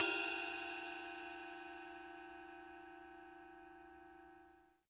CYM RIDE 6.wav